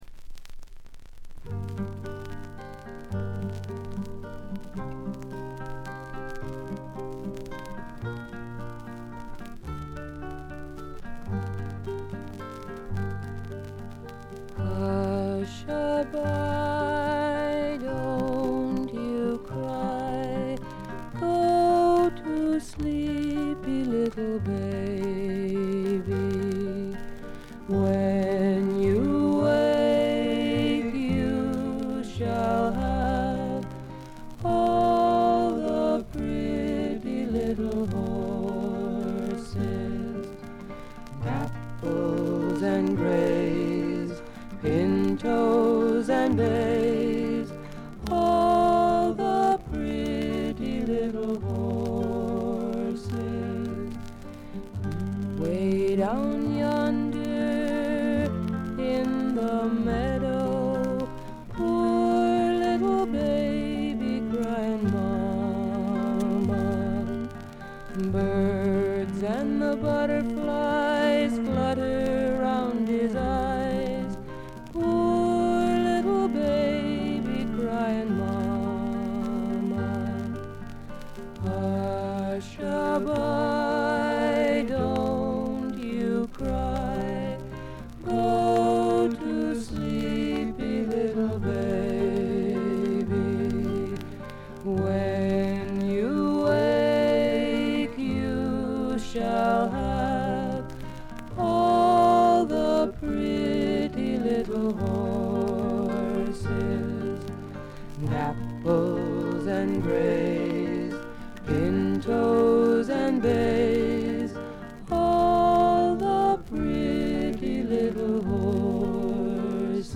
バックグラウンドノイズやチリプチ、プツ音等多め大きめ。
試聴曲は現品からの取り込み音源です。